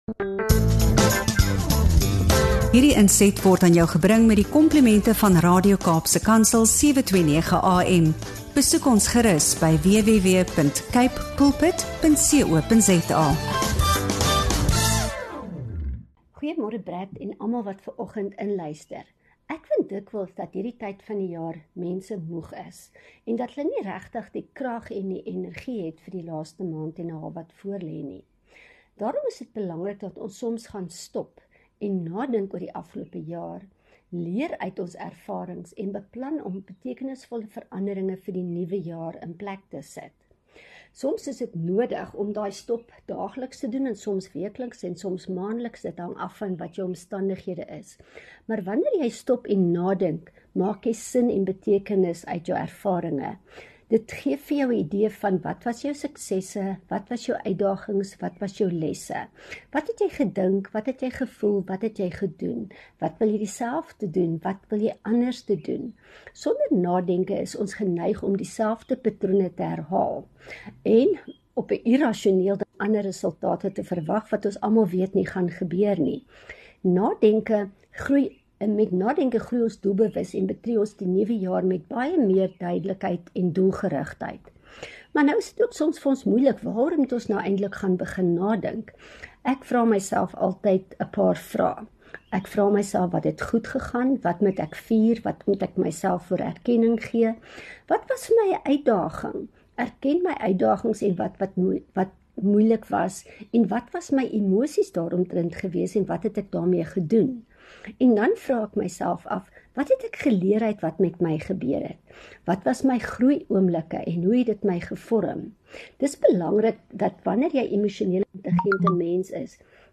GET UP & GO BREAKFAST - INTERVIEW SPECIALS